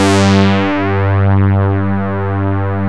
MOOG #5  G3.wav